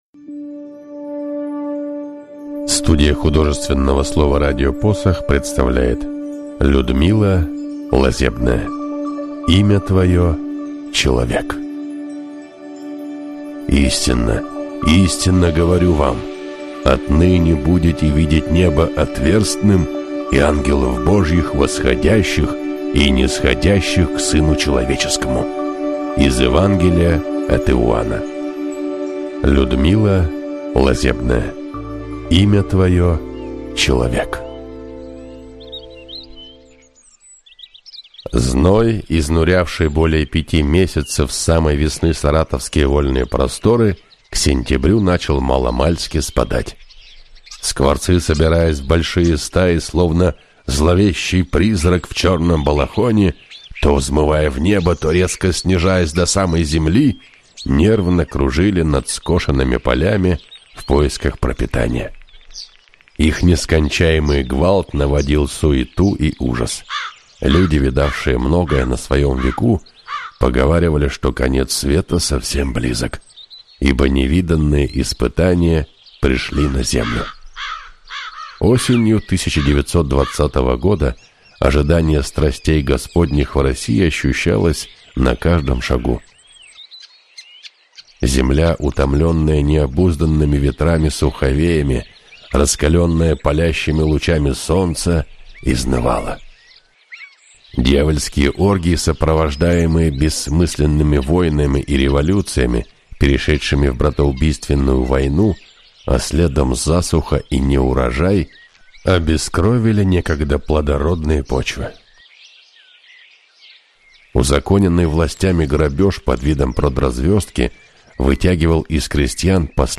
Аудиокнига Имя твоё – Человек | Библиотека аудиокниг
Прослушать и бесплатно скачать фрагмент аудиокниги